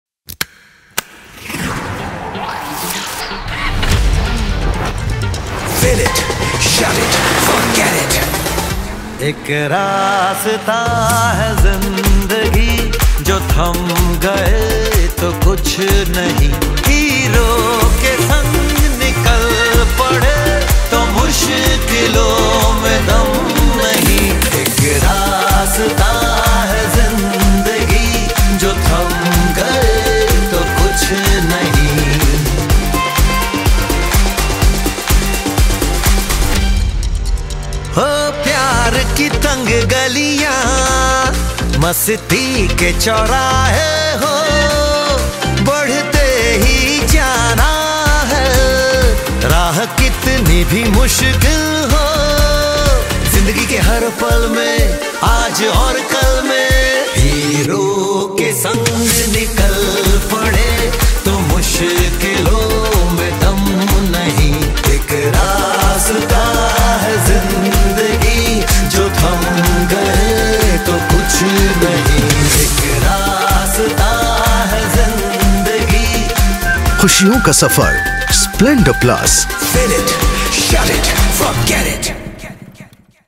Tv Ad Song